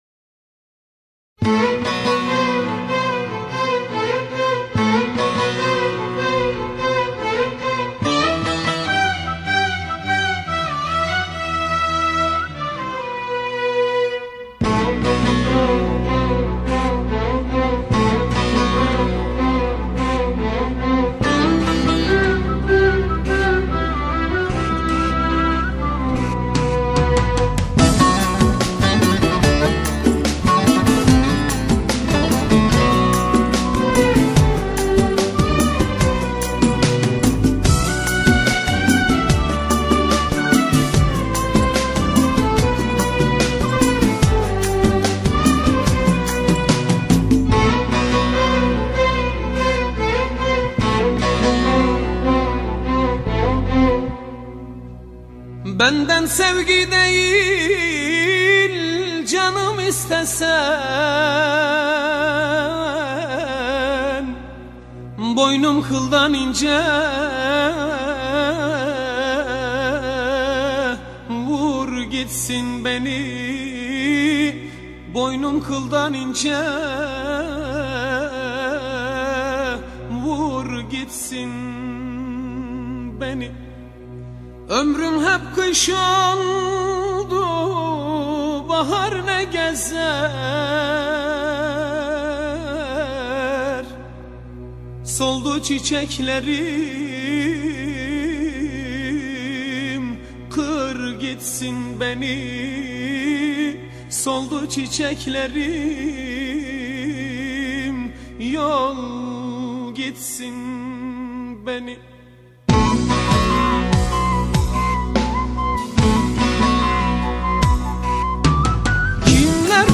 Arabesque, Turkish Folk Music